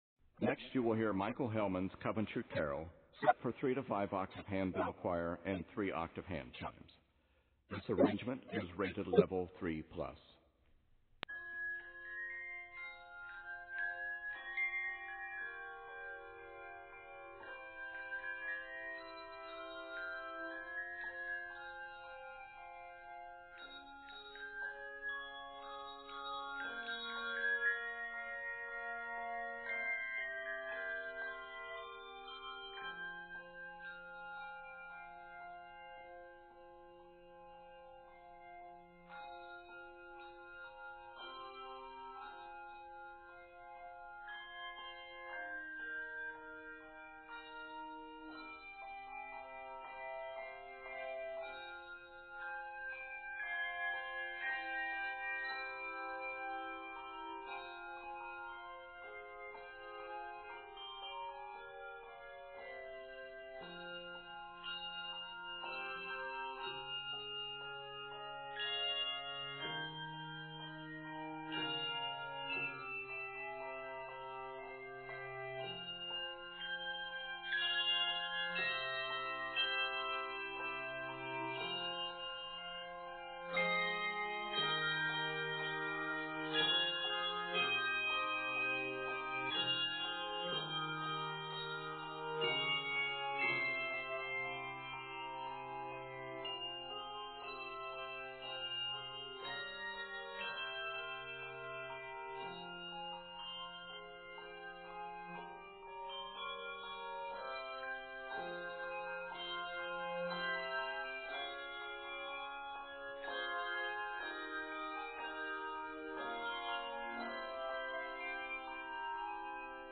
is scored in f minor and d minor.